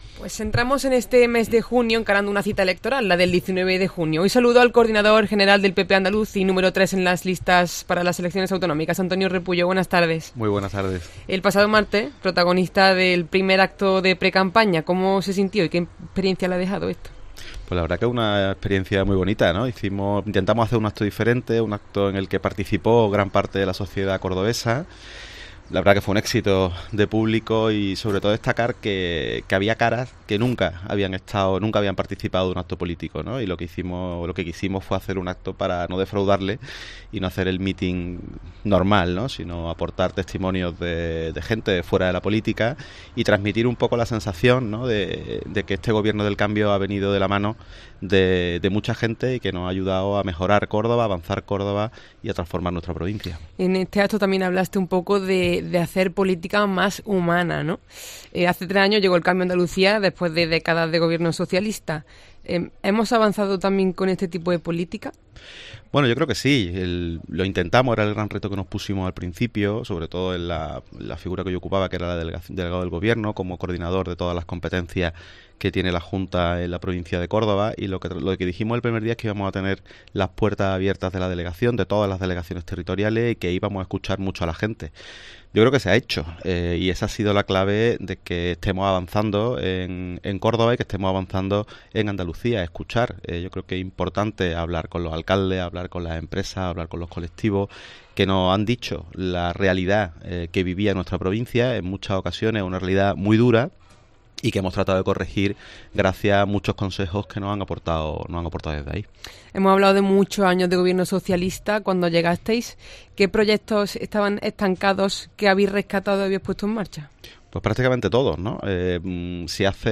Entrevista completa a Antonio Repullo, número 3 por Córdoba a las elecciones andaluzas y delegado de la Junta